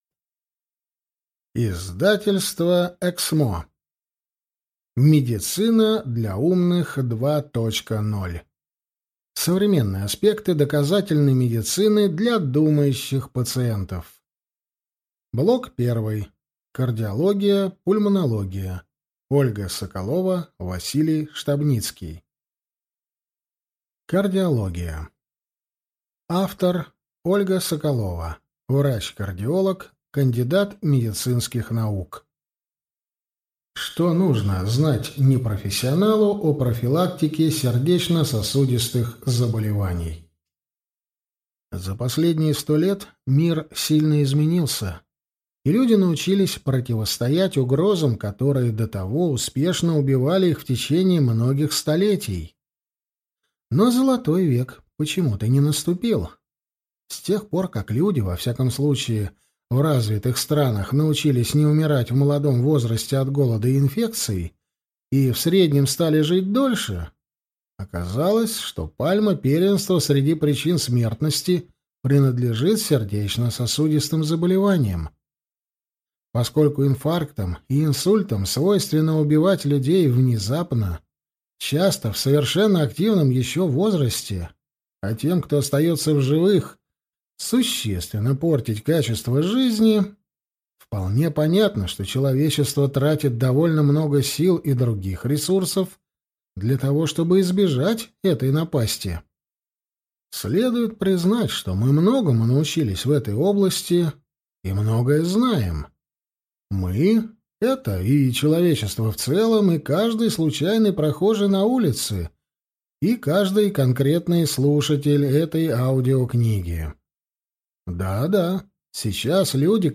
Аудиокнига Медицина для умных 2.0. Блок 1: Кардиология и пульмонология | Библиотека аудиокниг